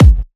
edm-kick-66.wav